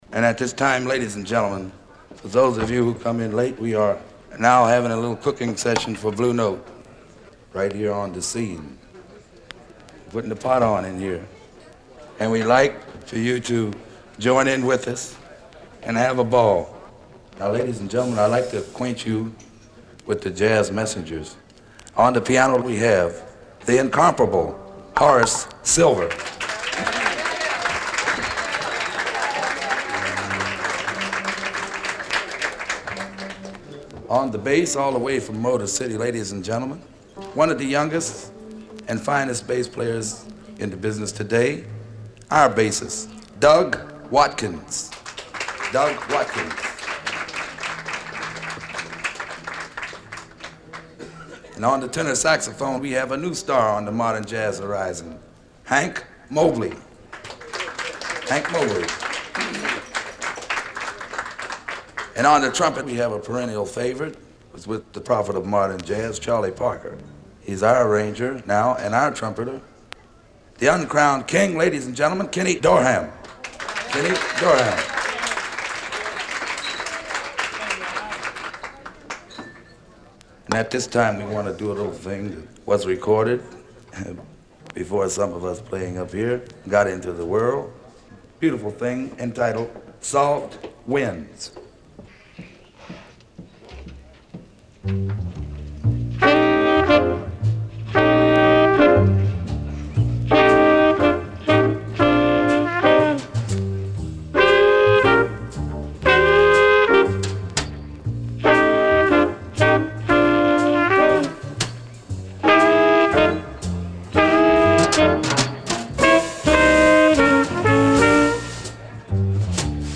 Recorded November 23, 1955 at Cafe Bohemia NYC